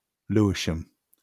Lewisam (/ˈlɪʃəm/
LOO-ish-əm; see below) is an area in the northeastern suburbs of Harare, Zimbabwe.